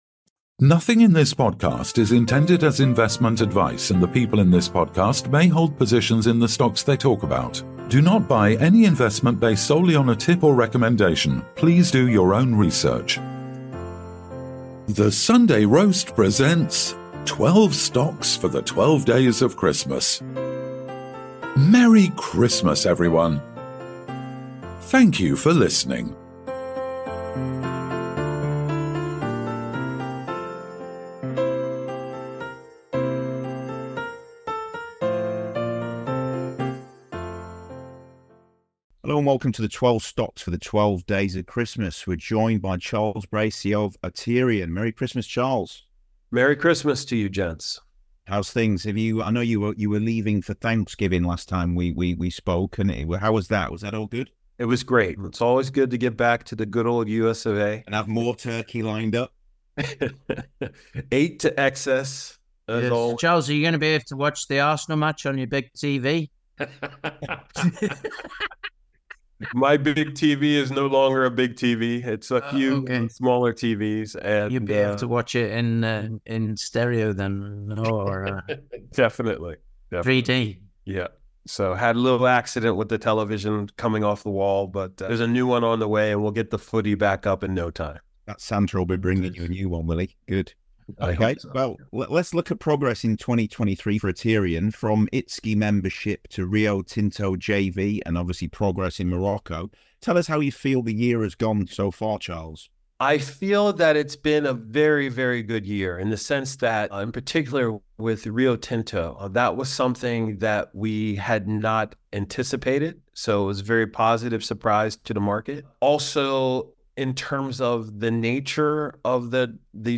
in a series of podcasts interviewing the various company CEO's and Chairmen